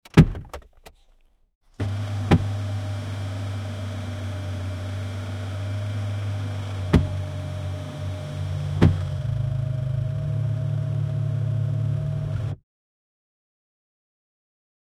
car tire deflating
car-tire-deflating-zvc36ap4.wav